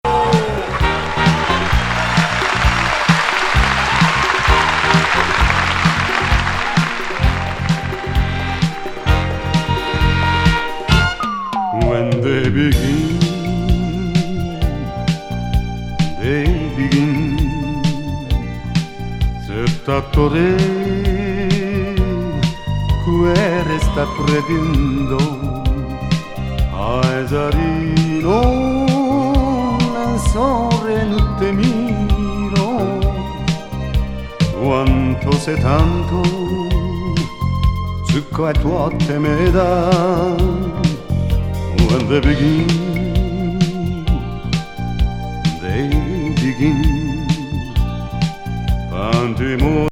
ブリット・ジャズ傑作。ファンキーにも展開するスピリチュアル・ジャズ風モダール。